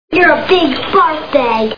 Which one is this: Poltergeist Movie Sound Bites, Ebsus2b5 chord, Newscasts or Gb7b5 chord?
Poltergeist Movie Sound Bites